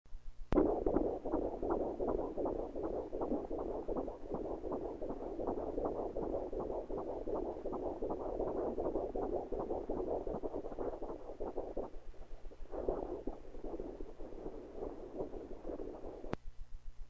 This being the 21st Century, we were able to record the baby's heartbeat with a rented Doppler!!
heartbeat.mp3